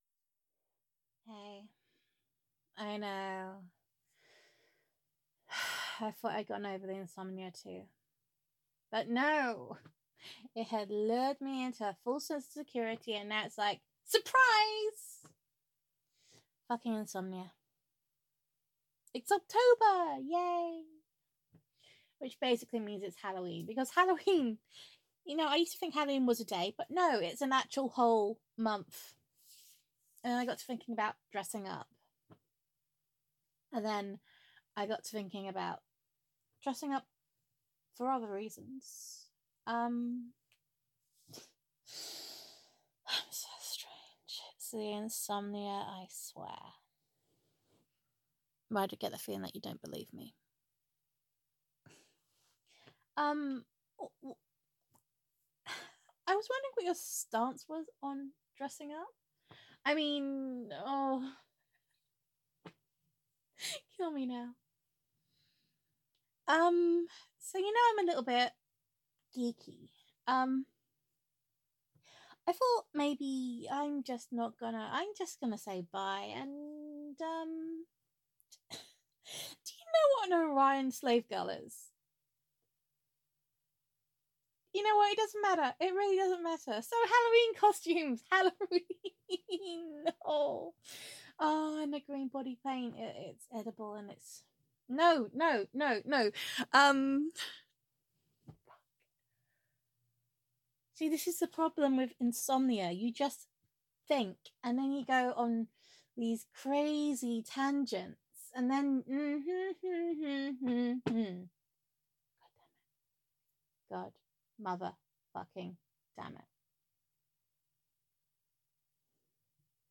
[F4A] Insomniac Revelations [Voicemails From Your Insomniac Girlfriend][Adorkable][Love Confessions][Gender Neutral][Girlfriend Roleplay]